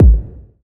Index of /90_sSampleCDs/Club_Techno/Percussion/Kick
Kick_13.wav